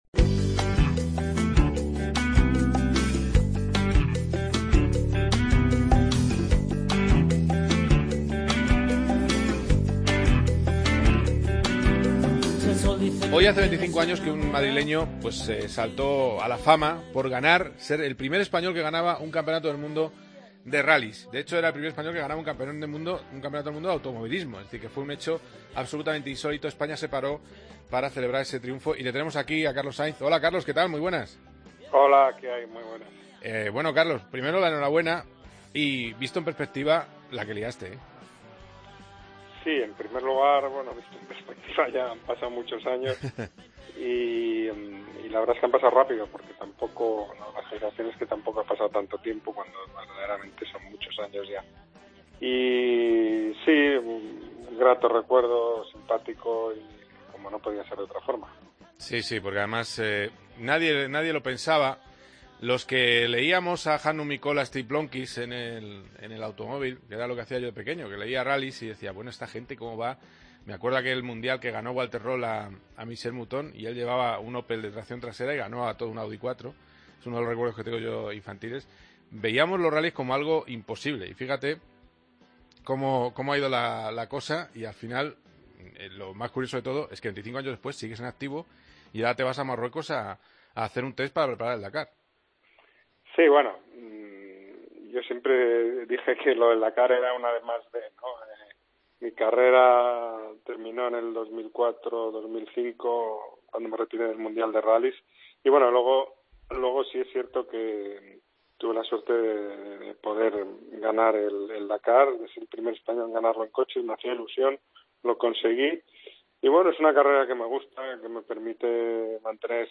Entrevista a Carlos Sainz, en COPE GP